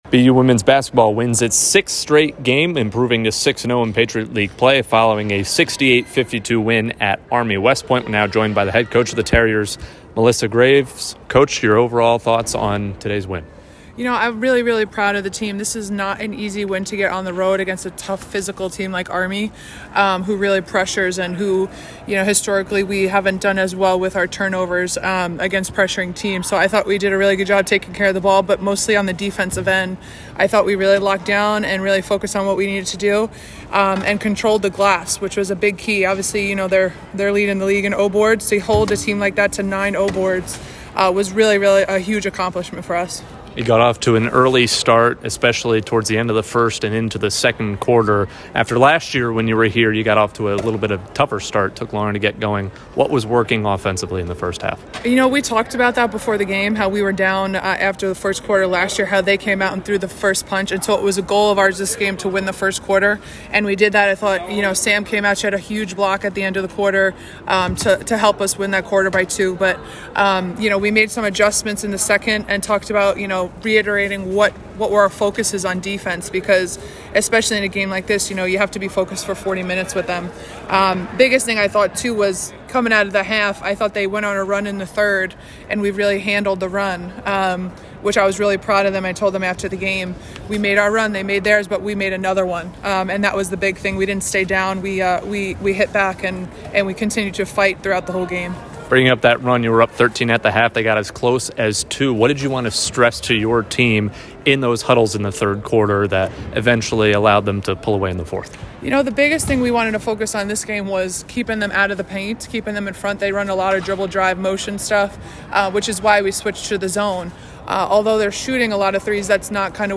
WBB_Army_1_Postgame.mp3